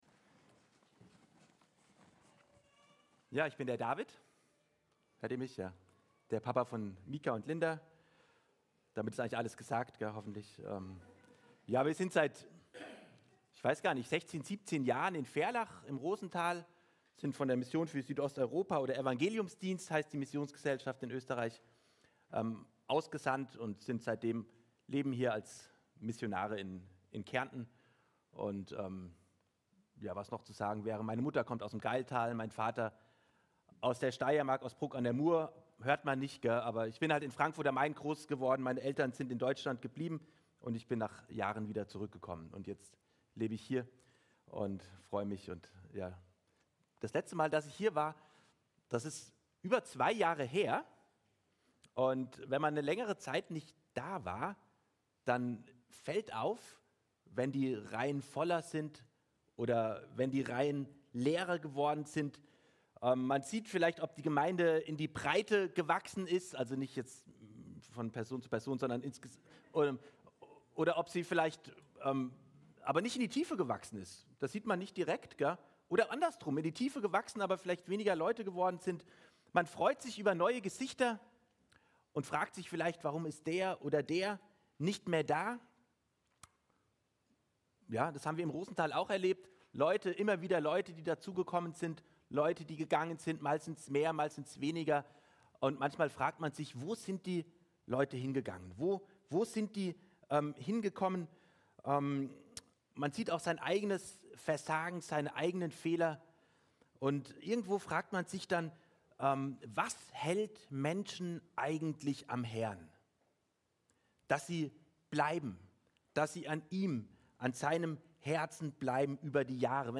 Aus der Serie: "Einzelpredigten 2025"